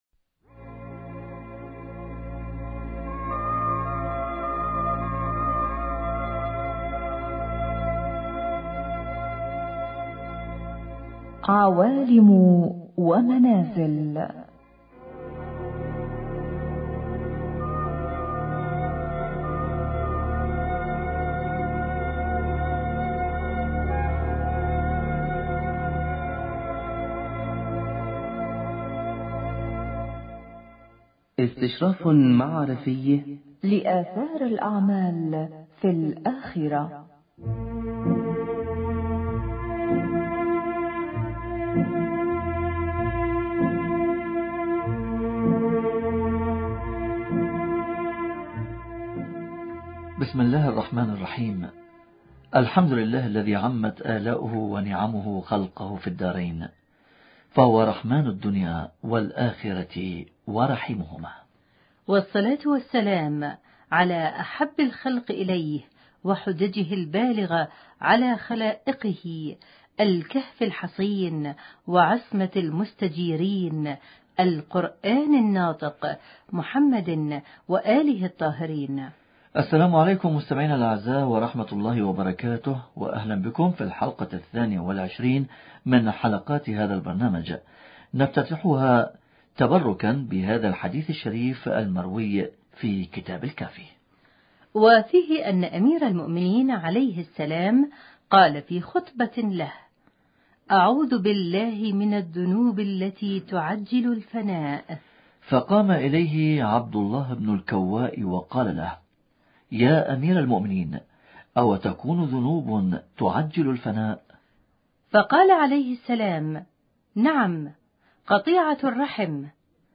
اتصال هاتفي مع خبير البرنامج